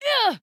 音效